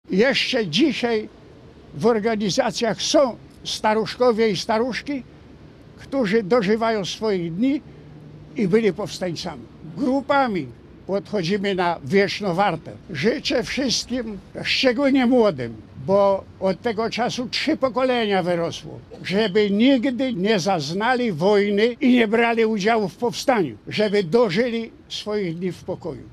Jednocześnie pod obeliskiem Powstania Warszawskiego na olsztyńskim Zatorzu rozpoczęły się uroczystości upamiętniające wybuch powstania.